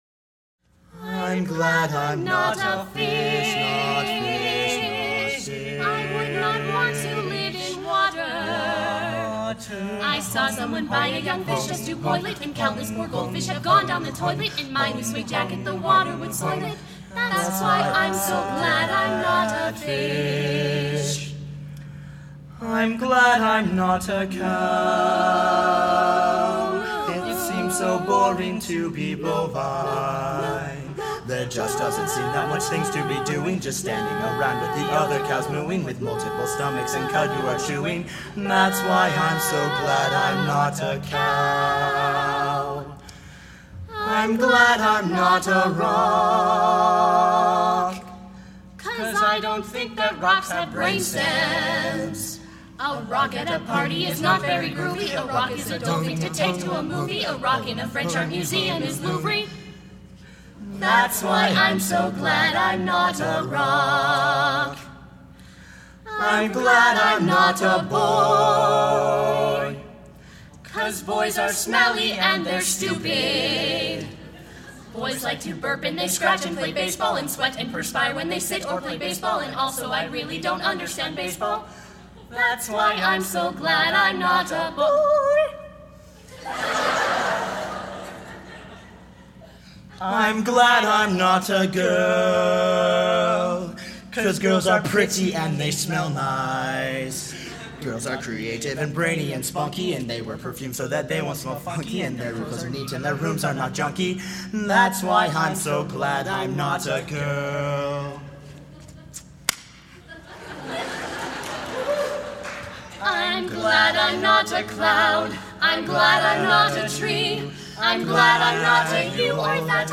SATB a ca - great for high school choirs or small ensembles